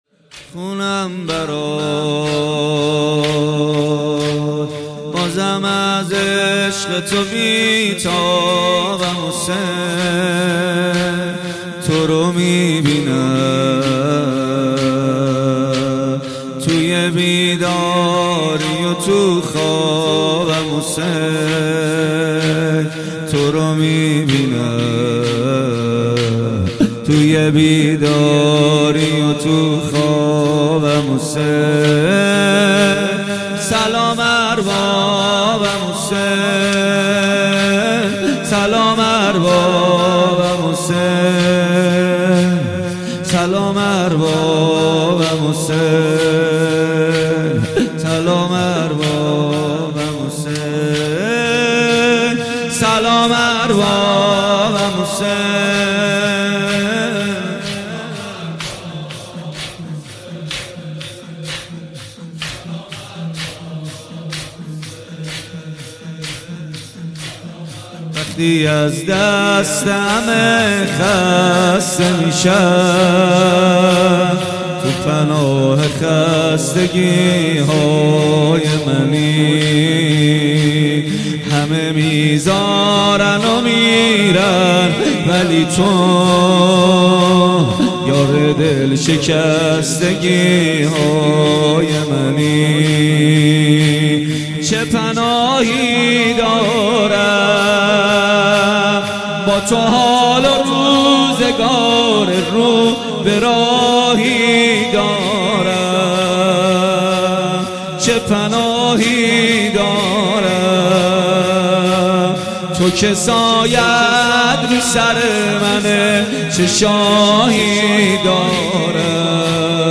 مداحی جدید کربلایی محمد حسین پویانفر مراسم هفتگی 3 دی ماه ‌۱۳۹۸ هیئت ریحانه النبی(س)